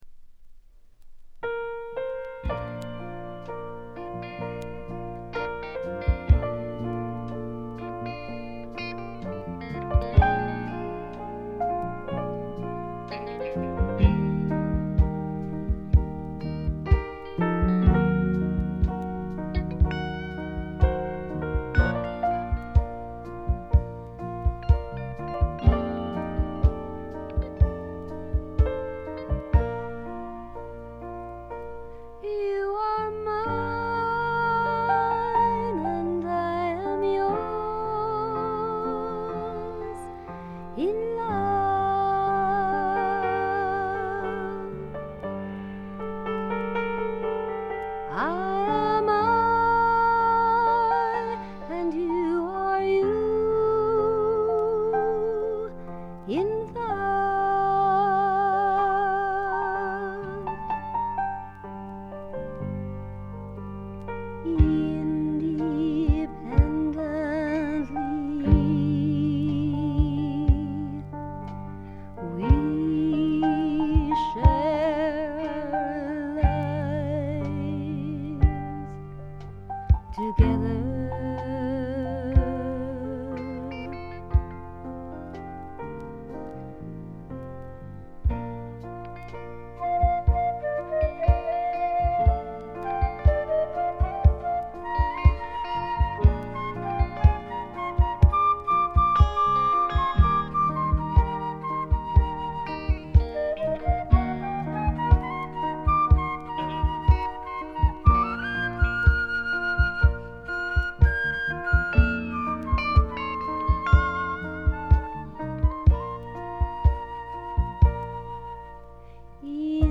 少し舌足らずな声で歌う楚々とした魅力が最高です。
基本は控えめなバックが付くフォークロック。
試聴曲は現品からの取り込み音源です。